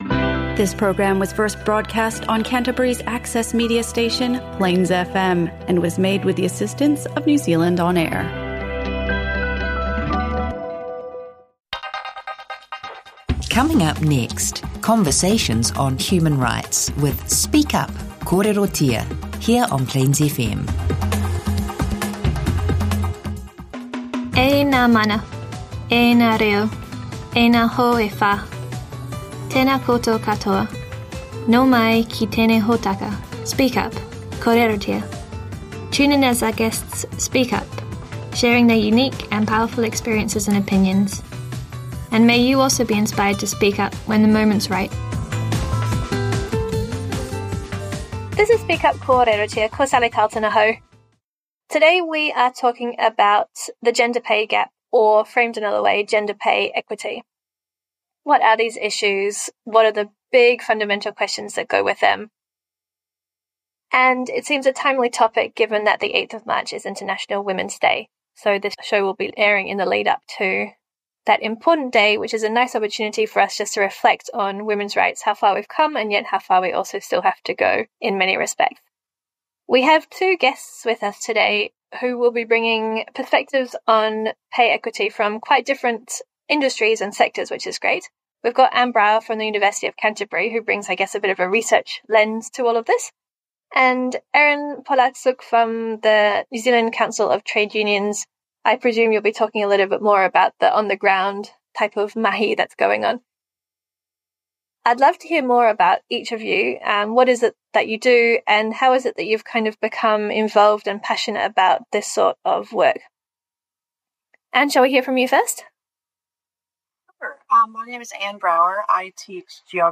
Christchurch City Libraries blog hosts a series of regular podcasts from specialist human rights radio show Speak up - Kōrerotia.